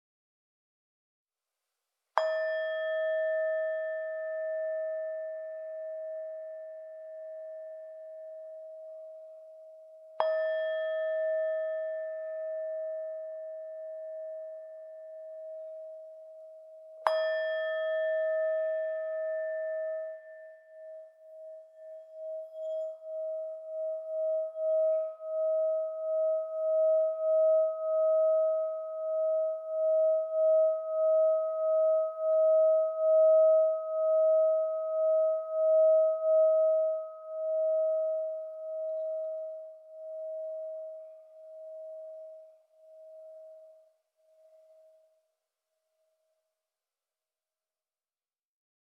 Meinl Sonic Energy Ornamental Series Singing Bowl - 400g - Black (SB-OR-400-BK)
The sounds produced are warm and relaxing, so singing bowls are frequently used worldwide in meditation practices and some yoga studios.